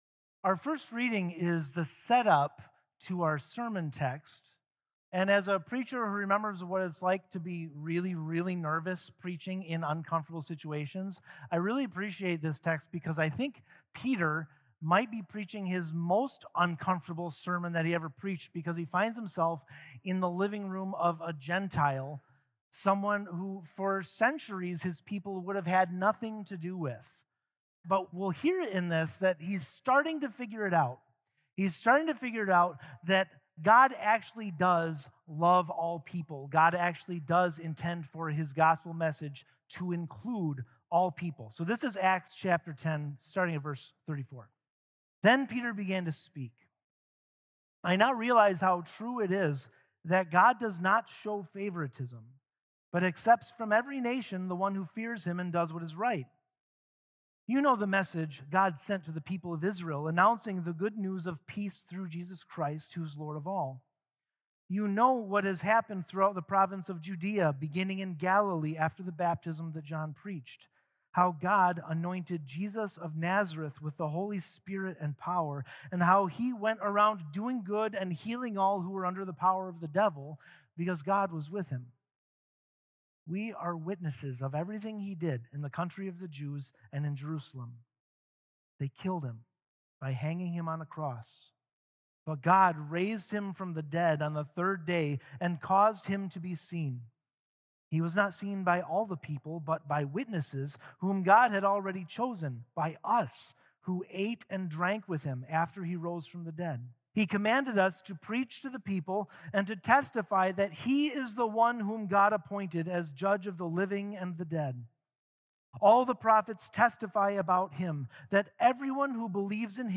Weekly Sermons from St. Marcus Lutheran Church, Milwaukee, Wisconsin